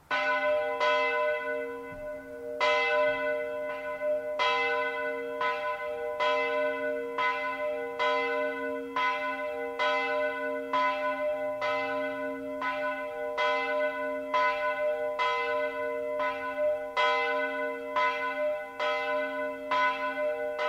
Andreas-Glocke
Andreasglocke.mp3